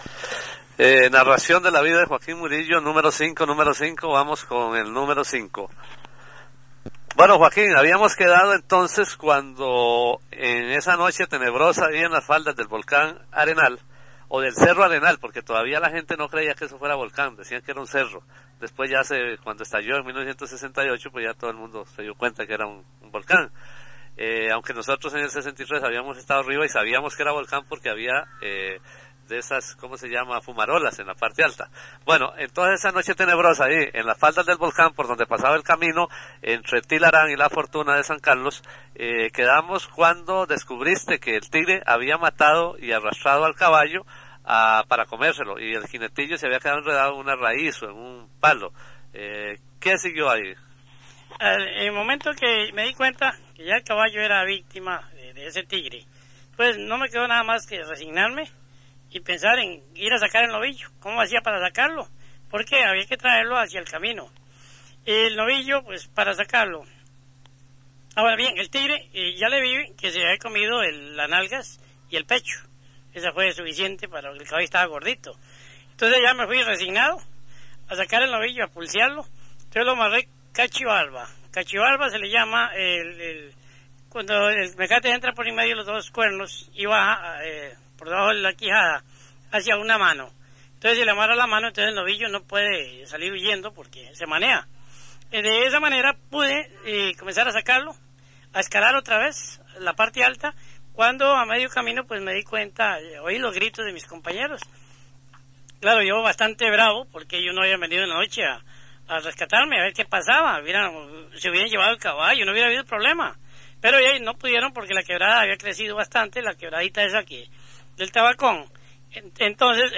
Notas: Casete de audio y digital